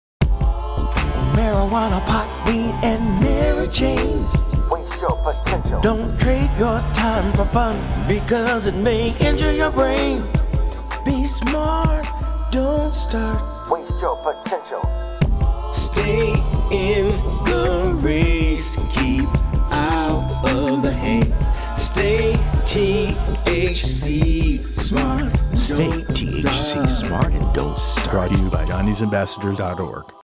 Television and Radio PSA Ads Free For You to Use!
RADIO